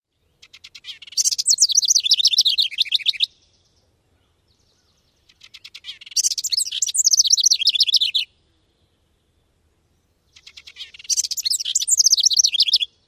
莺鹪鹩嘹亮的叫声